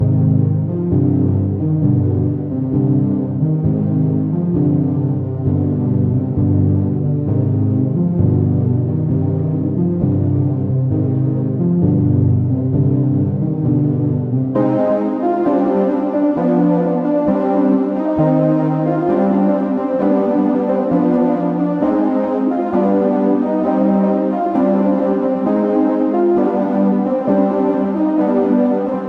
Tag: 66 bpm Trap Loops Synth Loops 4.90 MB wav Key : C